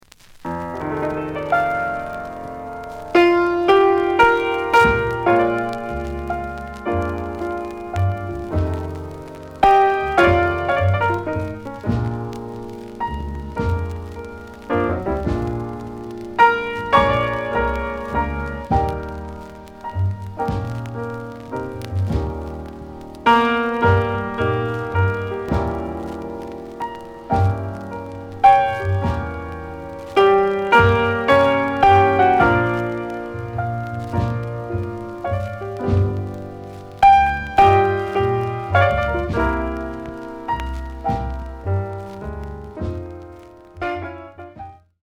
The audio sample is recorded from the actual item.
●Genre: Soul, 60's Soul
Slight click noise on both sides due to a bubble.)